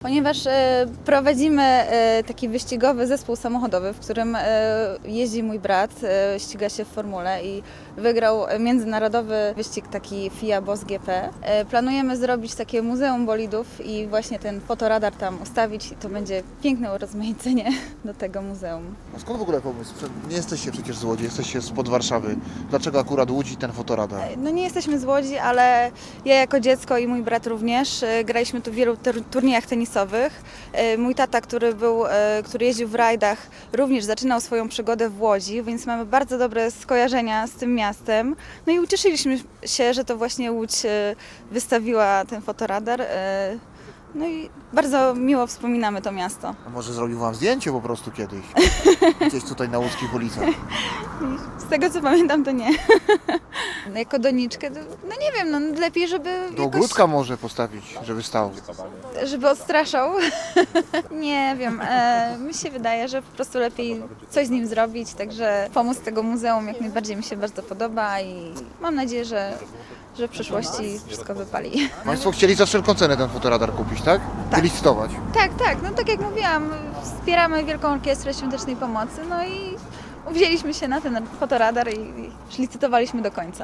Posłuchaj relacji naszego reportera i dowiedz się więcej: Nazwa Plik Autor Nowi właściciele fotoradaru audio (m4a) audio (oga) Fotoradar Straży Miejskiej w Łodzi został wylicytowany przez nowego właściciela za 11,5 tysiąca złotych.